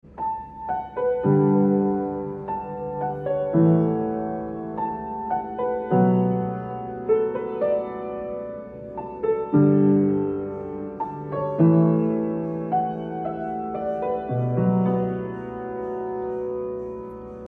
techno
piano